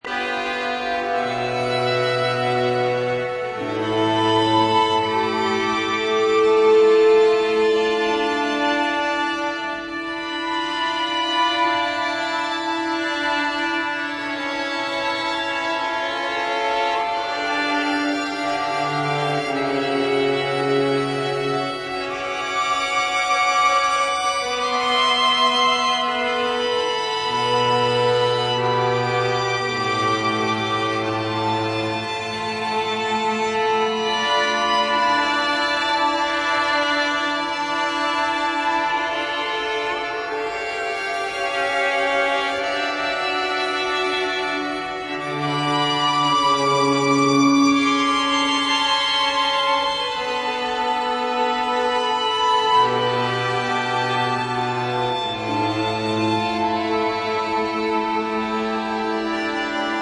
marking a clear step toward the ambient aesthetic